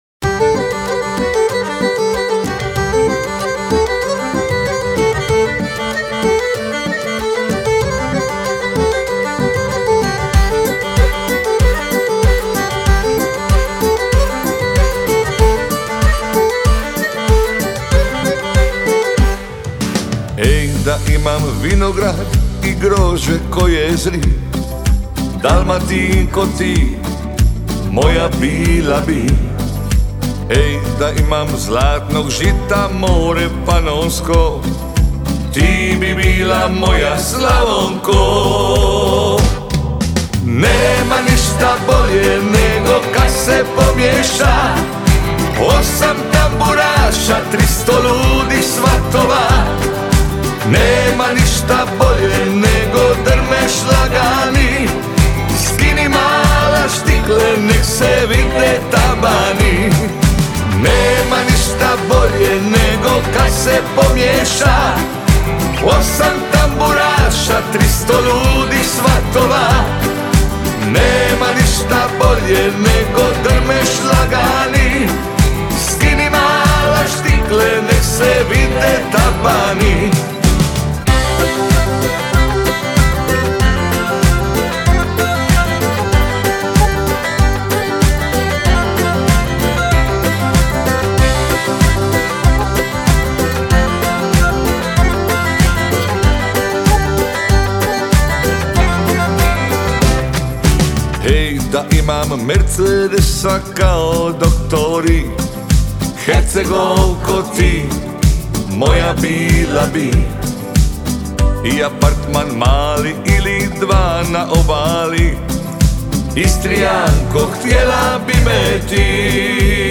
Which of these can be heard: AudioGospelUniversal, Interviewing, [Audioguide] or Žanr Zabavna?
Žanr Zabavna